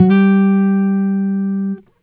Guitar Slid Octave 08-G2.wav